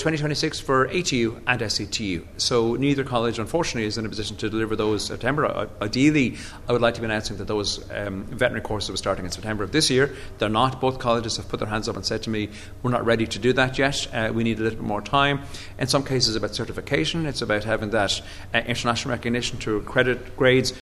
Speaking at the Teachers’ Union of Ireland’s Congress, Minister Lawless says it’s unfortunate the option is not available for students this year, but work is progressing: